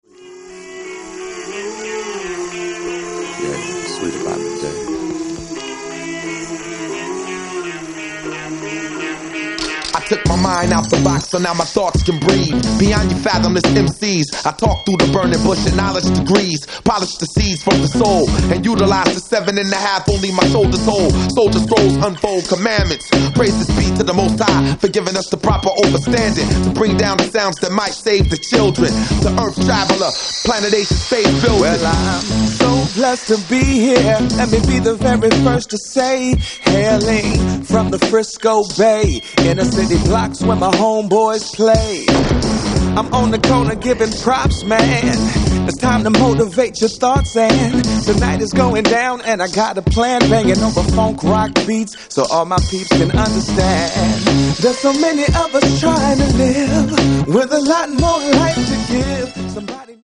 Nice hip hop/soul cut
Rhythm guitarist/vocalist